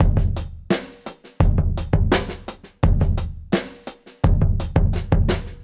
Index of /musicradar/dusty-funk-samples/Beats/85bpm
DF_BeatA_85-03.wav